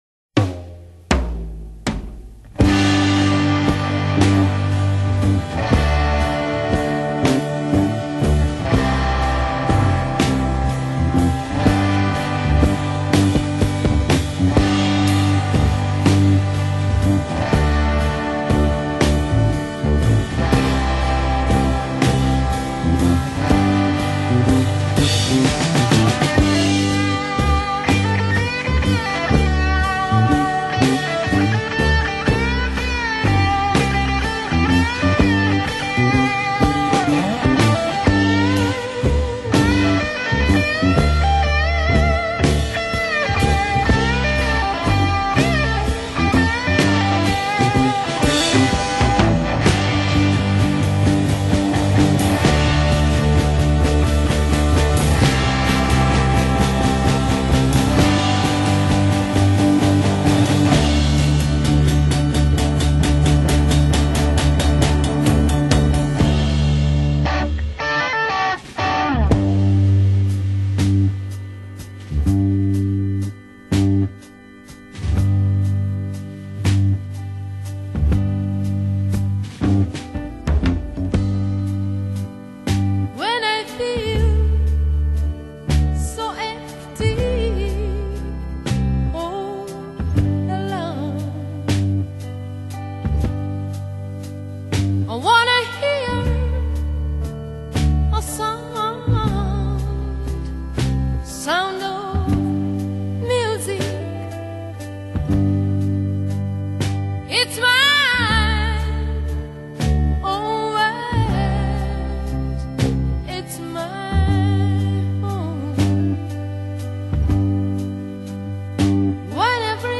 재즈 . 블루스